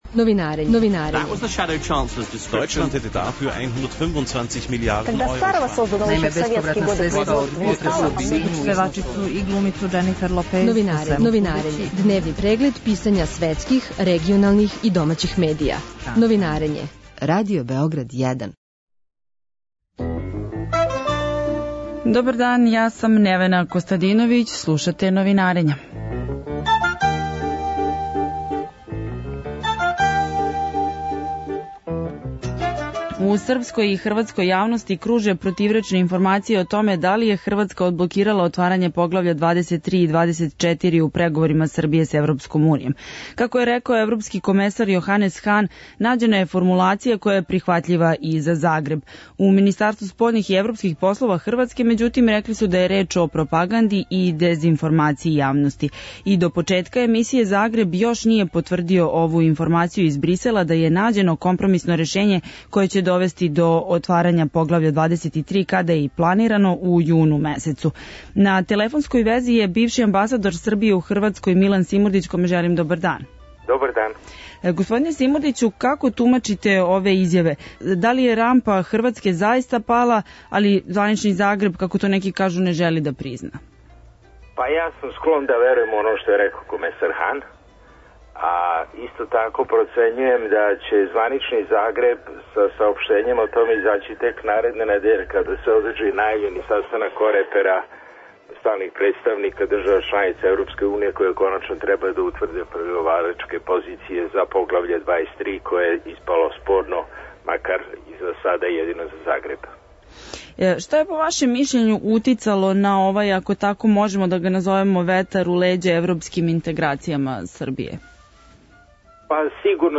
Гост Новинарења је бивши амбасадор Србије у Хрватској Милан Симурдић.